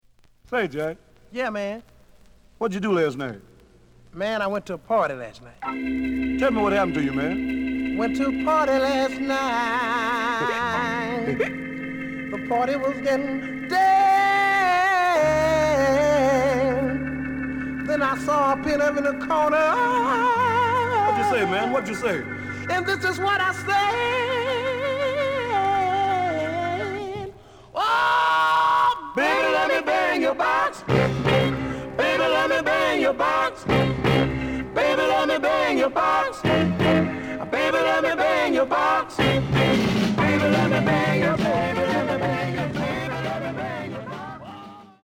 The audio sample is recorded from the actual item.
●Genre: Rhythm And Blues / Rock 'n' Roll
Slight damage on both side labels. Plays good.)